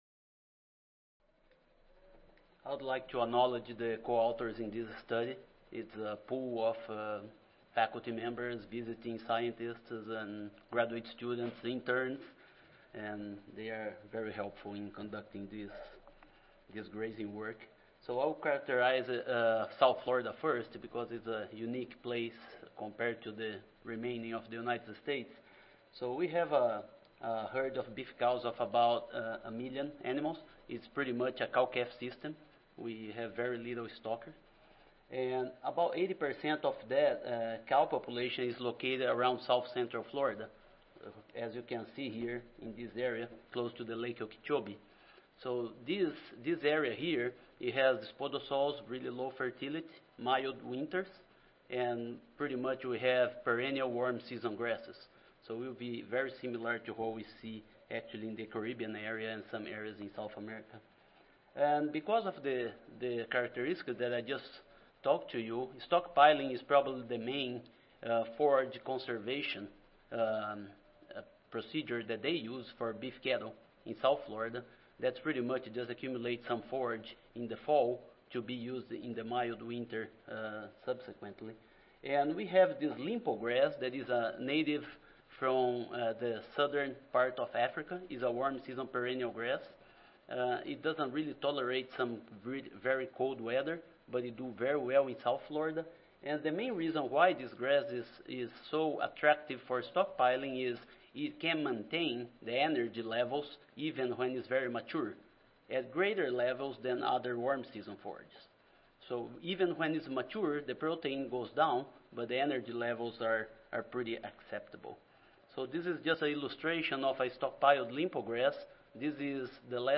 Session: Forage and Grazinglands: III (ASA, CSSA and SSSA International Annual Meetings (2015))
Recorded Presentation